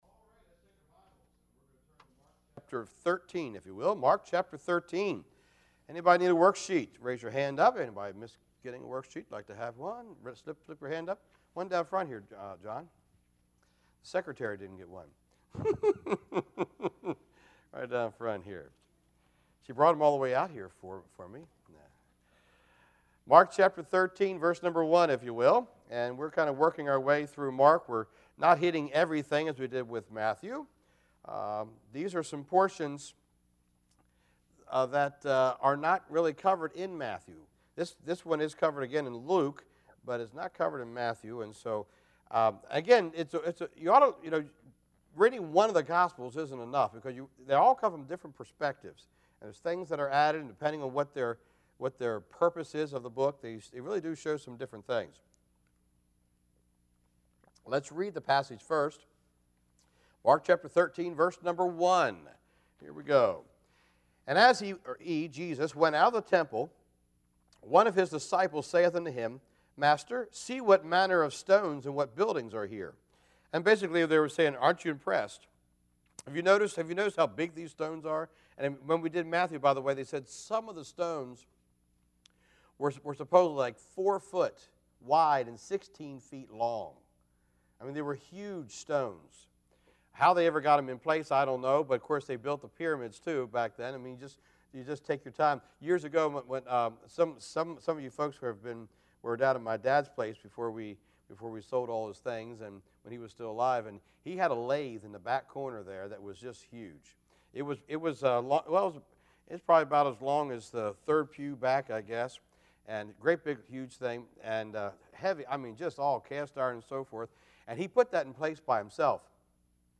Walking in the Word Passage: Mark 13:1-14 Service Type: Wednesday Evening